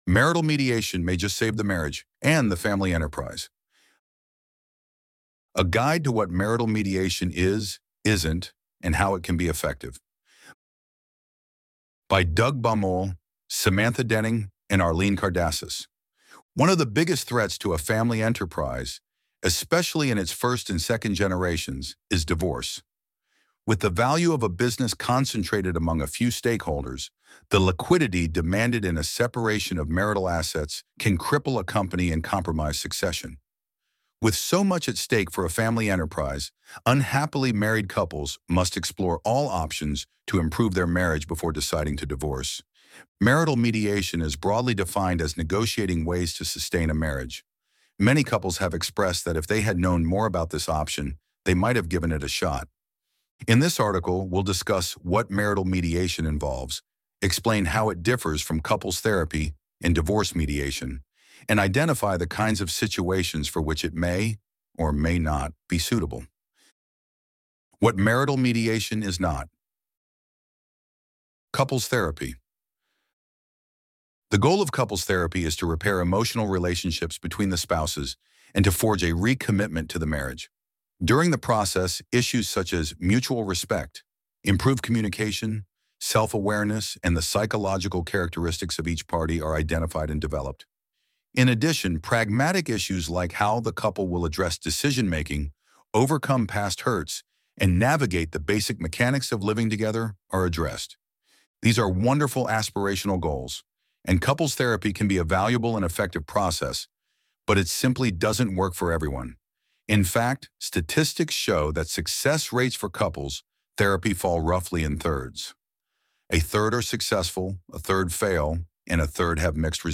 Marital Mediation May Just Save the Marriage━and the Family Enterprise A guide to what marital mediation is, isn’t, and how it can be effective Loading the Elevenlabs Text to Speech AudioNative Player...